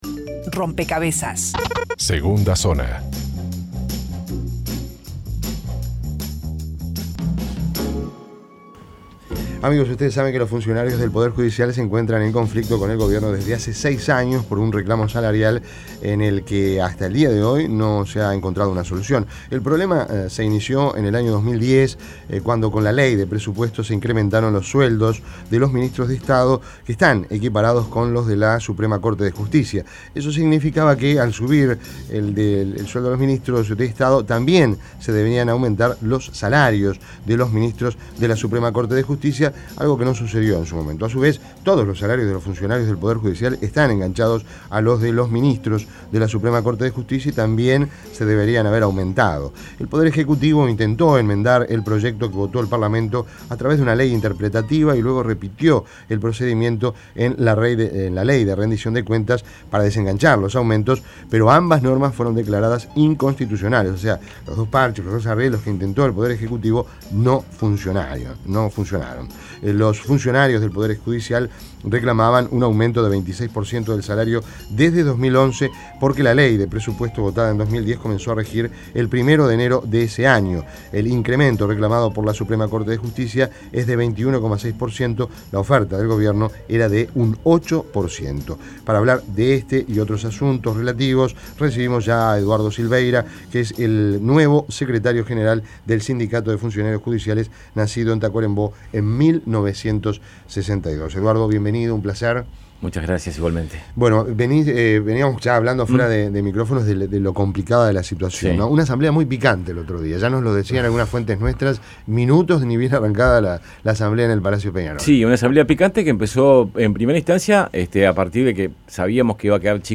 Entrevista en Rompkbzas Legisladores sabían que ley sobre sueldos judiciales desataría conflicto Imprimir A- A A+ El conflicto por la deuda que tiene el Estado con funcionarios judiciales parece no tener un final cercano.